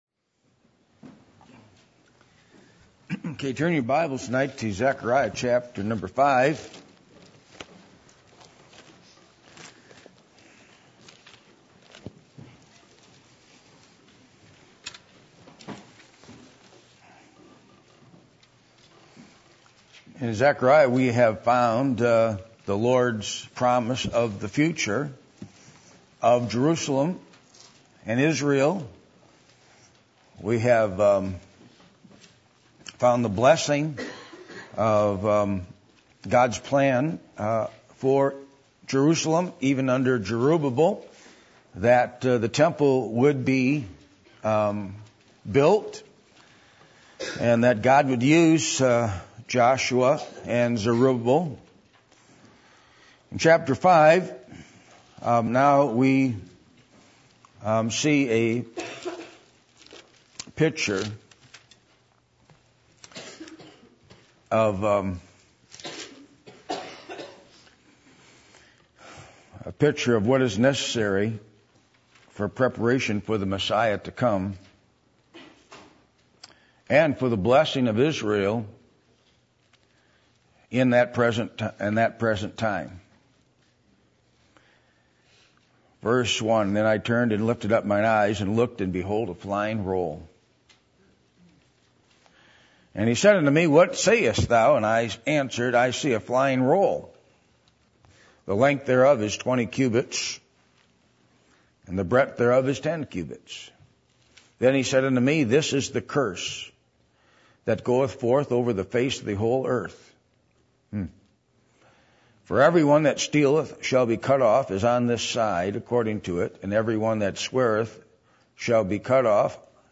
Passage: Zechariah 5:1-11 Service Type: Sunday Evening %todo_render% « Jesus